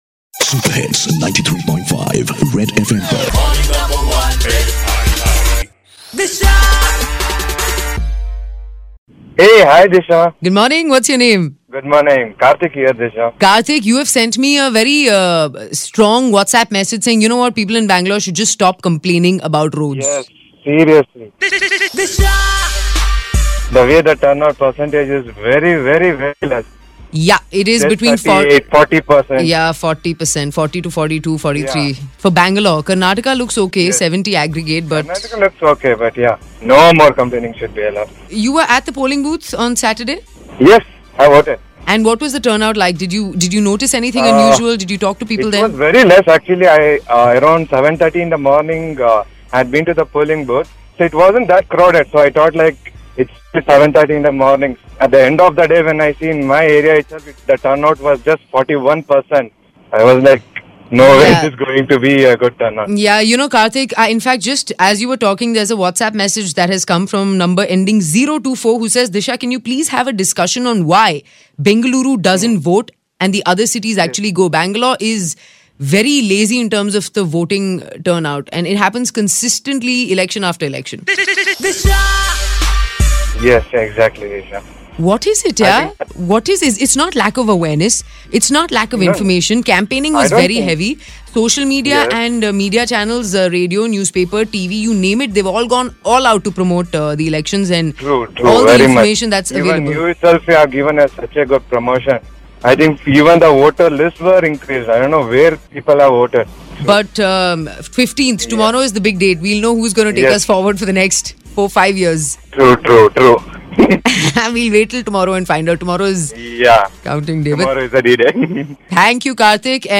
A listener who is upset because of Voter Turn Out being so less